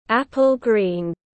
Màu xanh táo tiếng anh gọi là apple green, phiên âm tiếng anh đọc là /’æpl gri:n/.